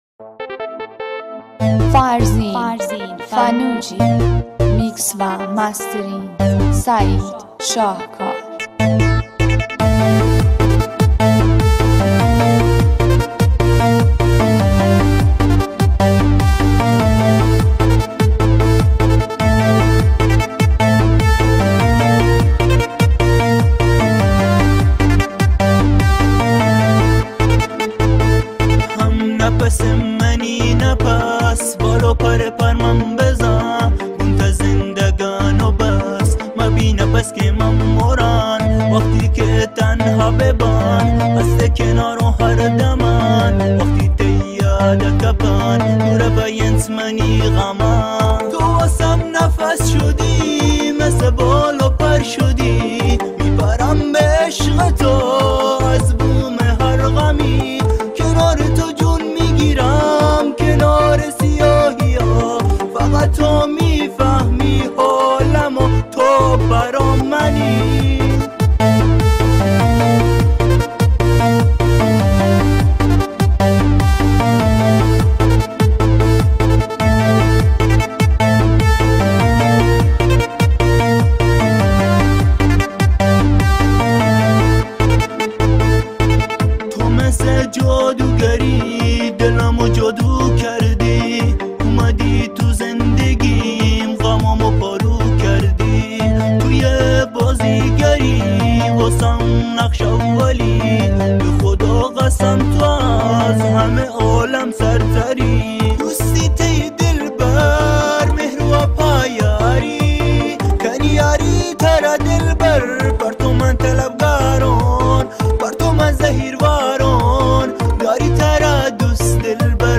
اهنگ بلوچی قشنگ صوتی